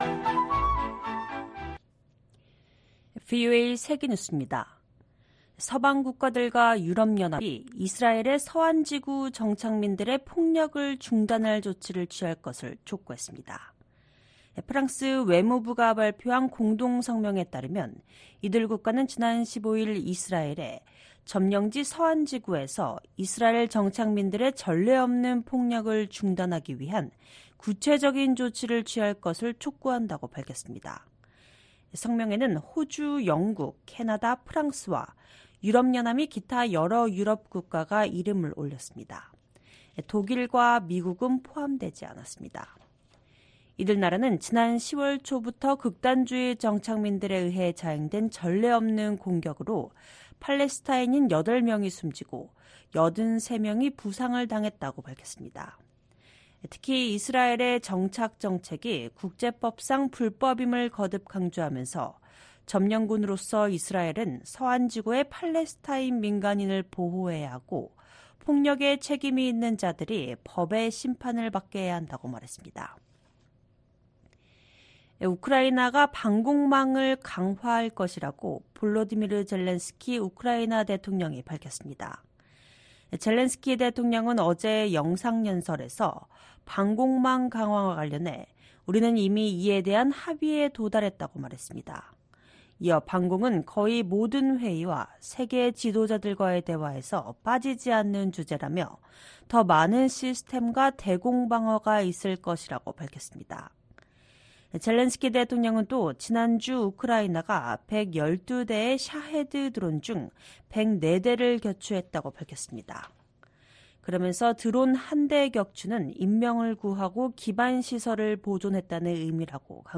VOA 한국어 방송의 일요일 오후 프로그램 3부입니다.